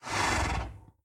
horse_idle3.ogg